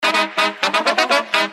• Качество: 320, Stereo
громкие
Саксофон
труба
Труба? Саксофон? Непонятно...